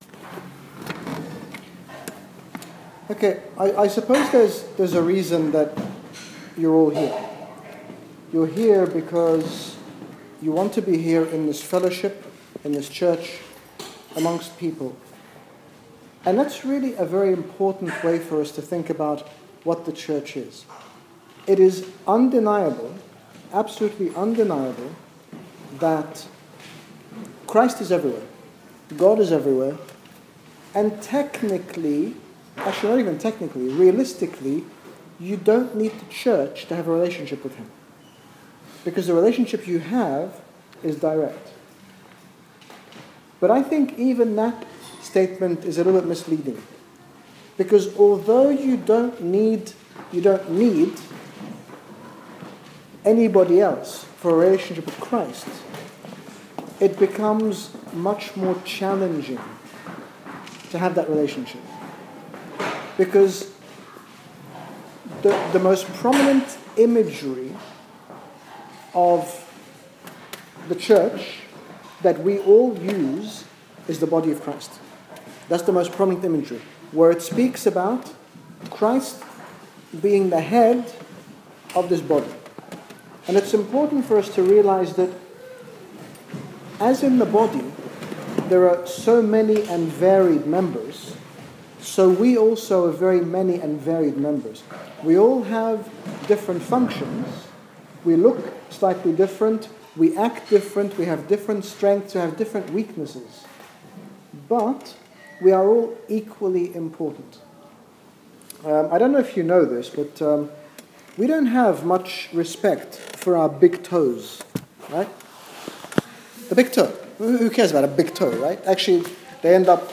In this talk His Grace Bishop Angaelos, General Bishop of the Coptic Orthodox Church in the United Kingdom, speaks about the support we can experience in the Church, through the Body of Christ, highlighting the fact that we were not created to be alone without the support of one another. Download Audio Read more about Supported by the Church - HG Bishop Angaelos - Chicago 2015